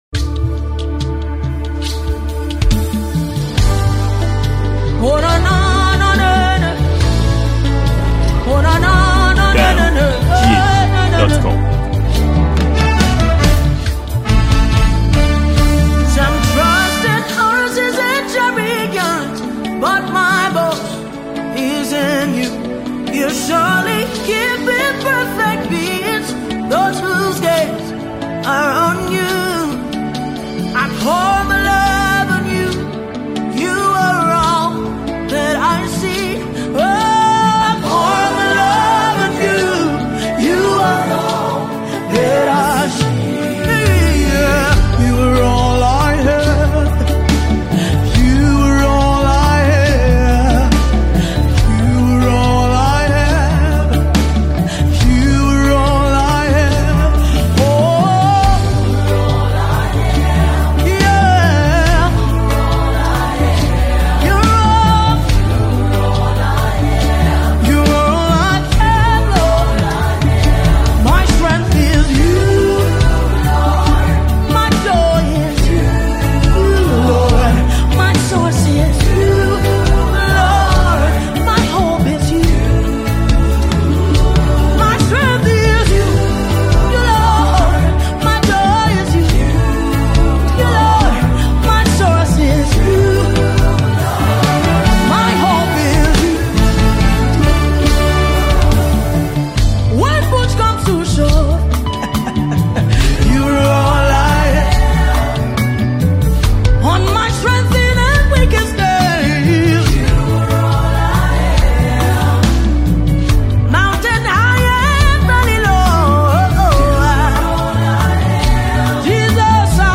Nigerian gospel recording artist